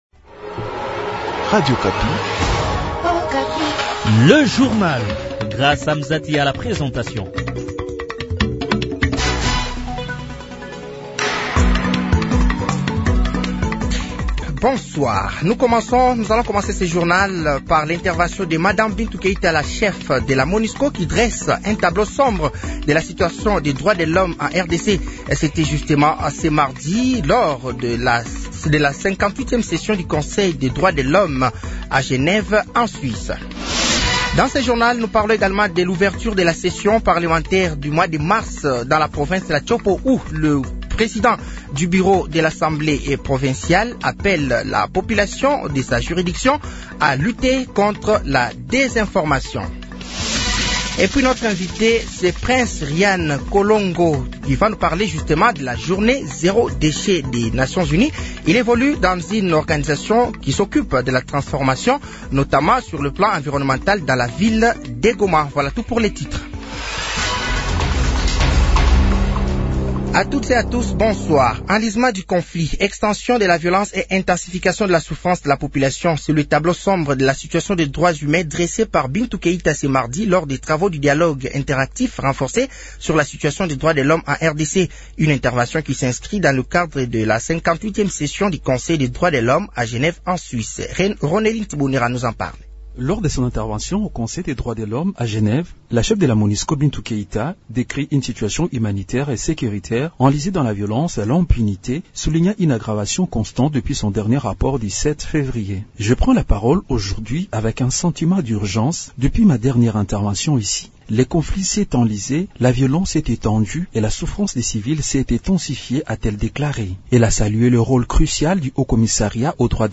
Journal français de 18h de ce mardi 01 avril 2025